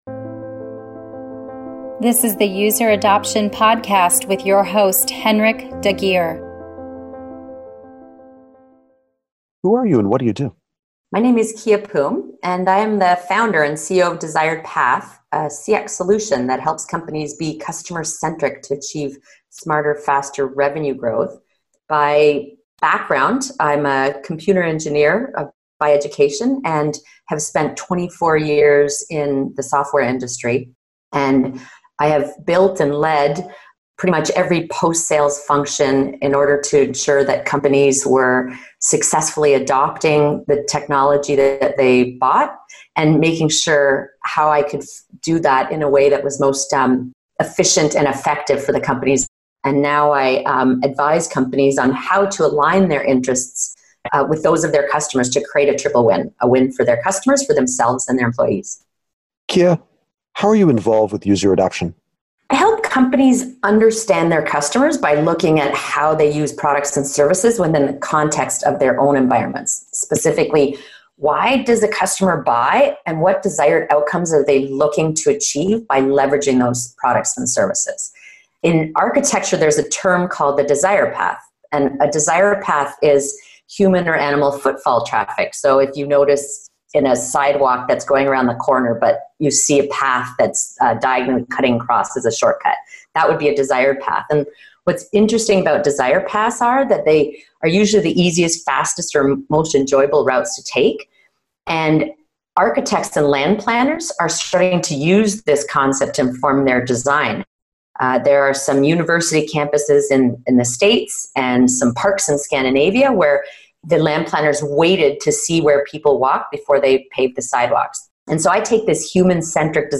Listen to the User Adoption Podcast interview